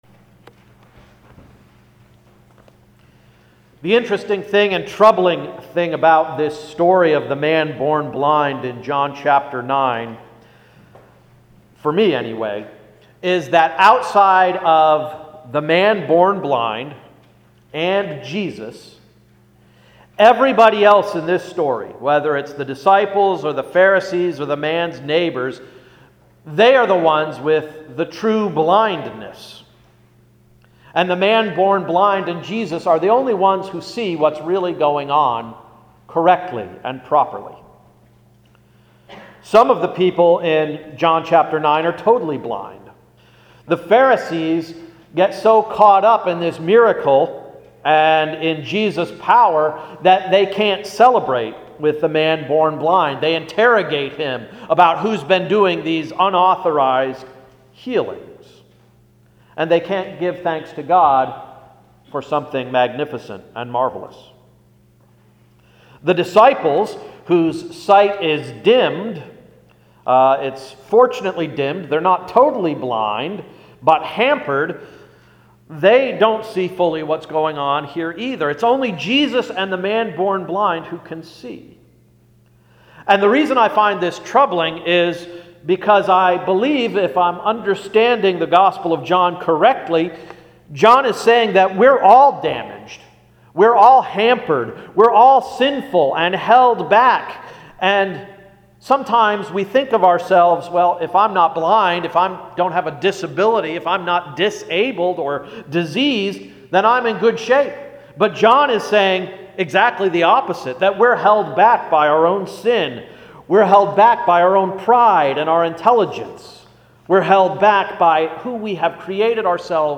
Sermon of March 30–“Who is Whole?”